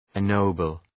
Προφορά
{en’nəʋbəl}